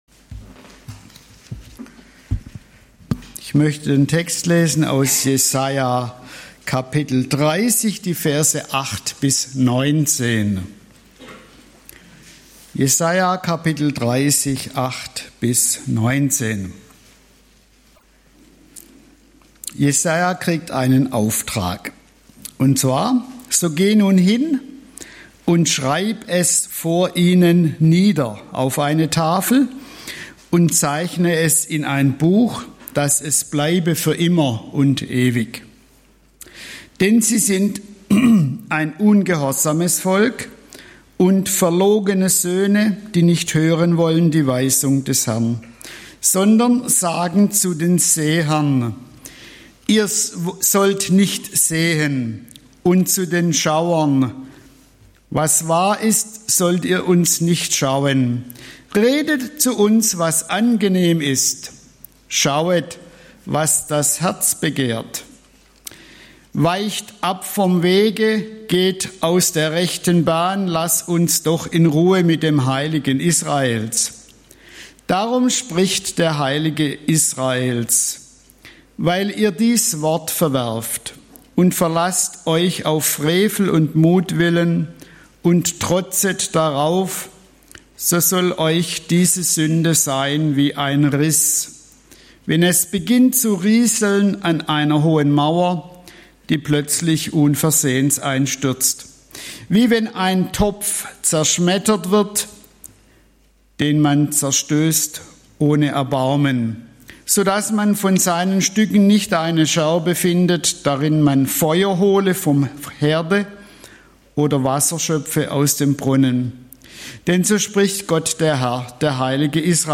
Der ewig gültige Schwur (Jes. 30, 8-19) - Bibelstunde